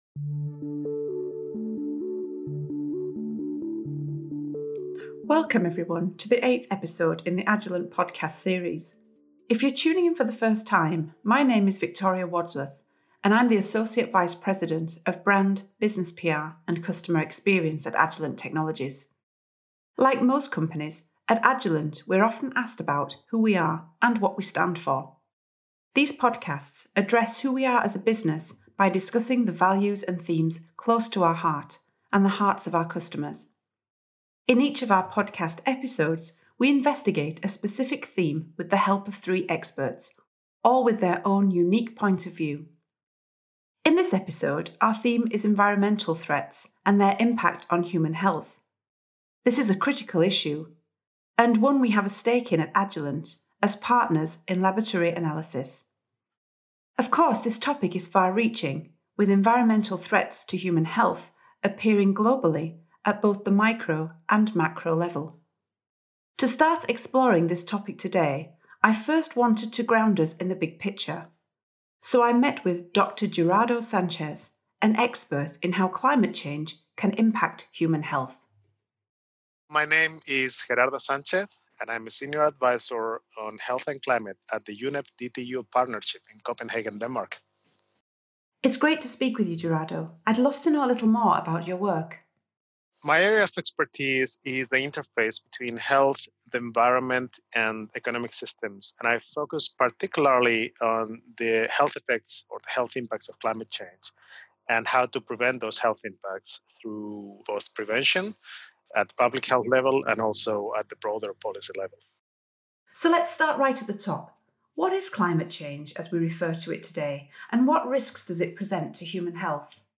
In this episode, we hear directly from experts working at the forefront of research on environmental contaminants and severe weather events to better understand the potential impact of environmental threats on human health.